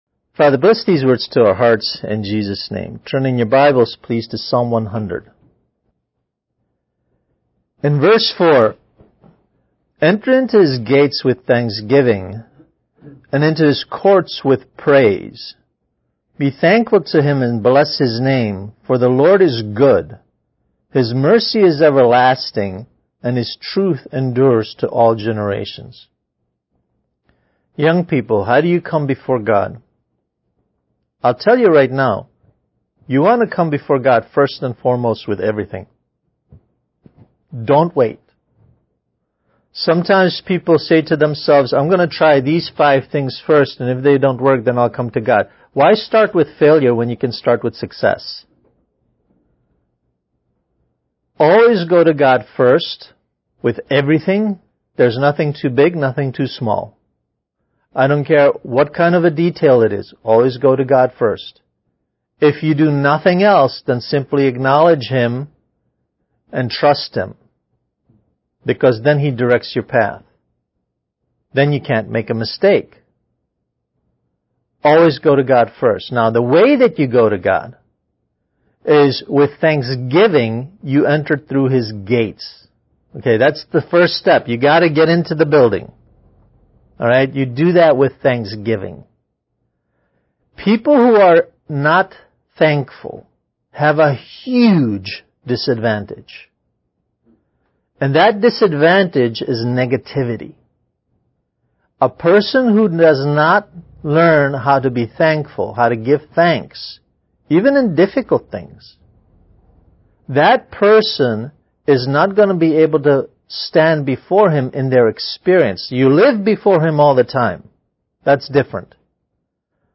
Kids Message: Good Thinking